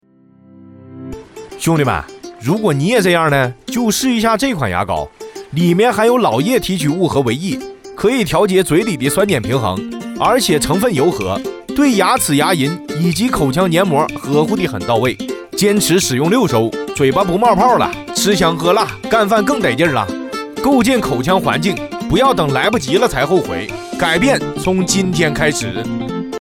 多风格男配，擅长专题、宣传、解说、汇报、走心、MG动画、广告等各类商业配音，以及动漫、游戏角色类配音。